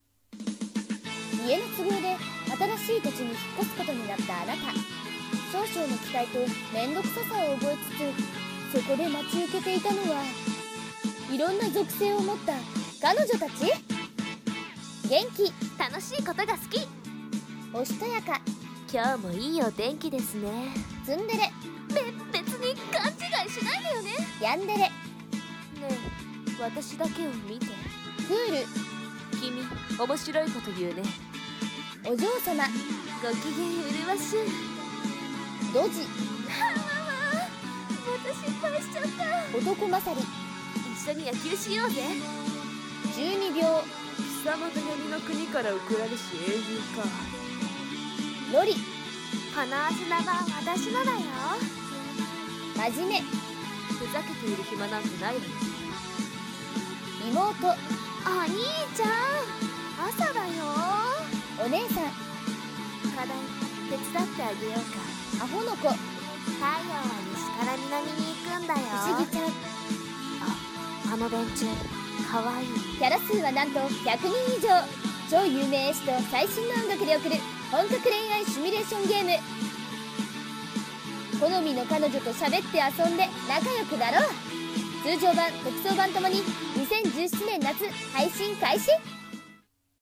【ゲームCM風声劇】属性彼女っ♪